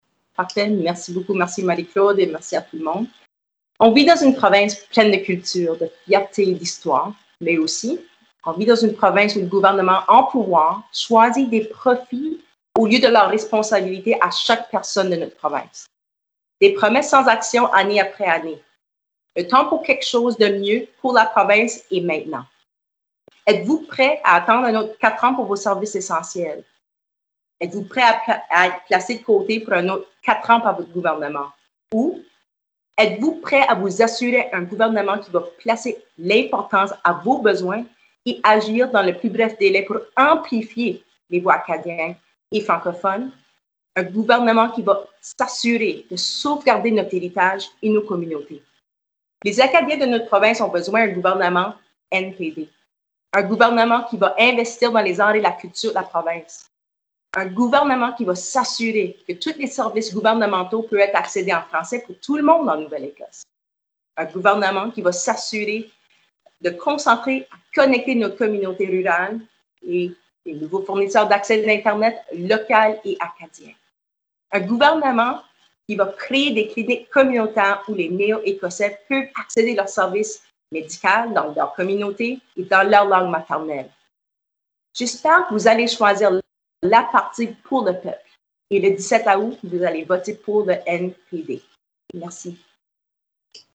Débat électoral : retour sur les moments importants
Une vingtaine de personnes étaient également présentes en tant que spectateurs.